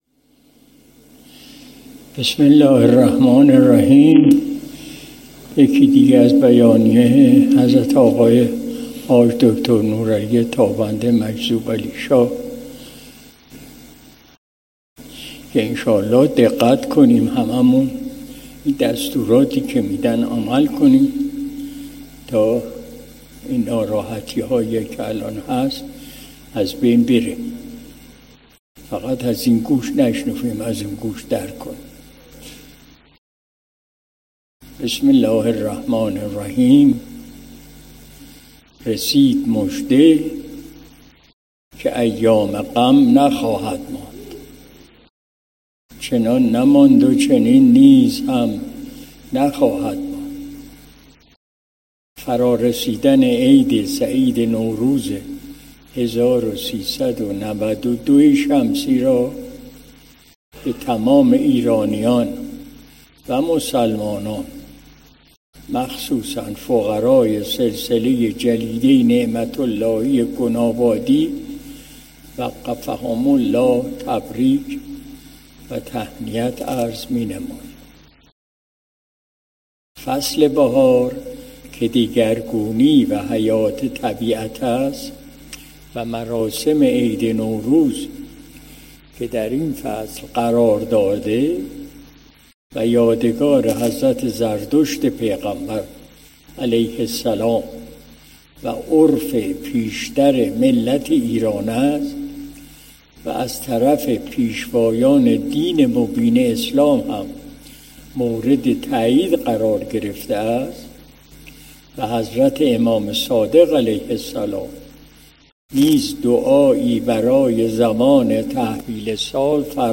مجلس شب دوشنبه ۹ بهمن ماه ۱۴۰۱ شمسی